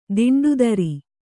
♪ diṇḍudari